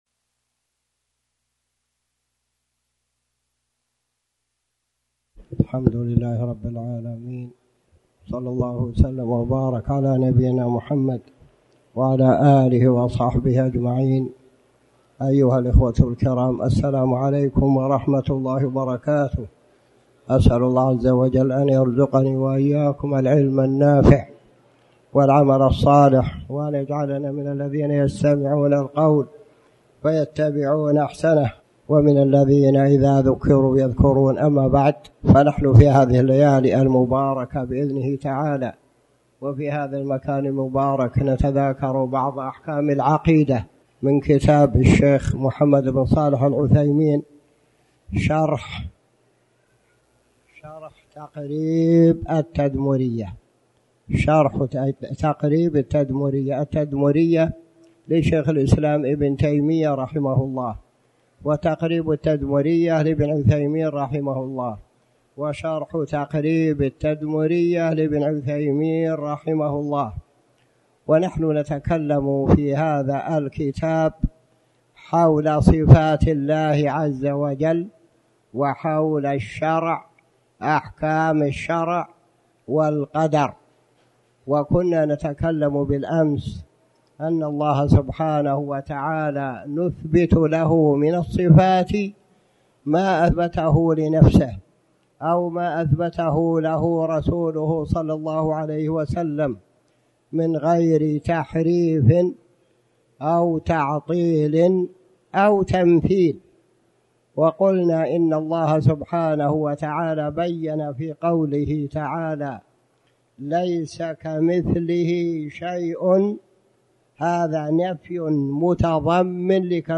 تاريخ النشر ٢ ذو القعدة ١٤٣٩ هـ المكان: المسجد الحرام الشيخ